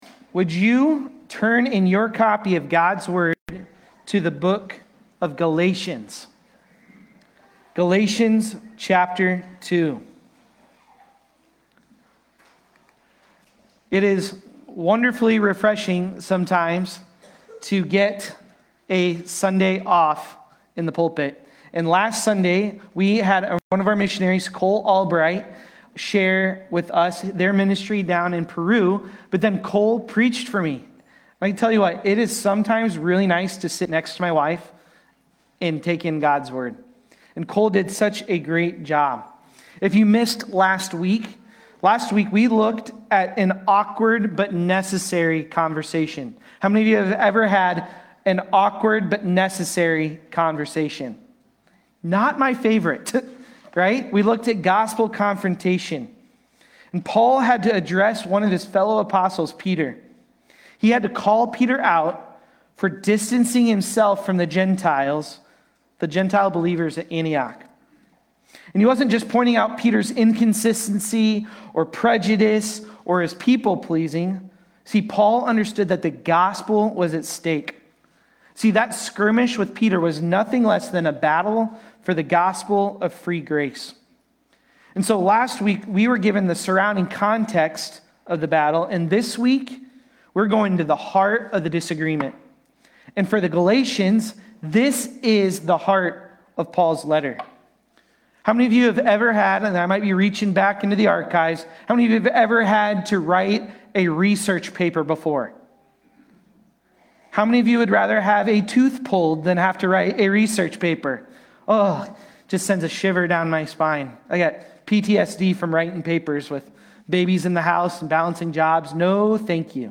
Galatians-2.15-21-Sermon-Audio.mp3